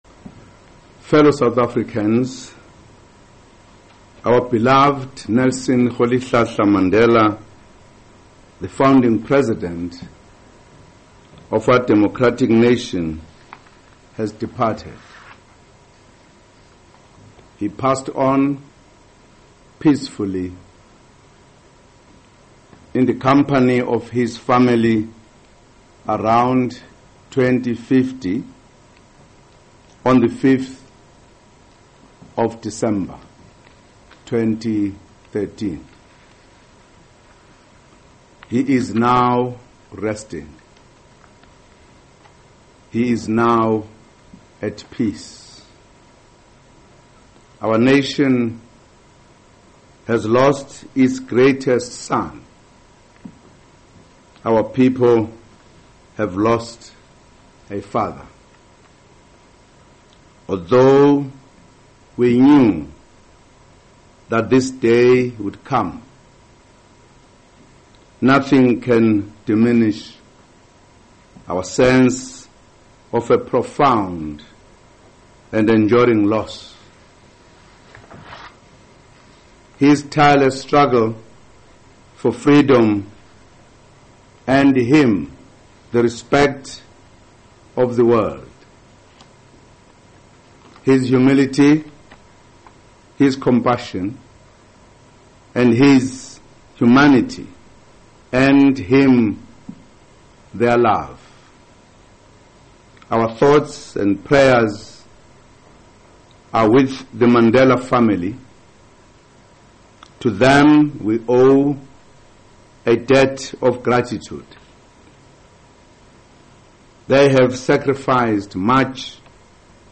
Zuma - Mandela - announcement